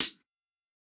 Index of /musicradar/ultimate-hihat-samples/Hits/ElectroHat B
UHH_ElectroHatB_Hit-04.wav